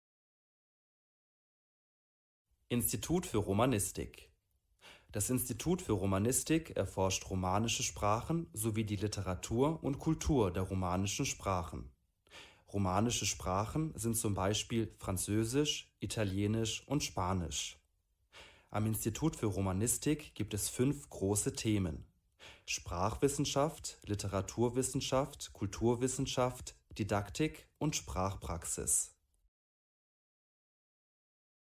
Hörversion der Seite.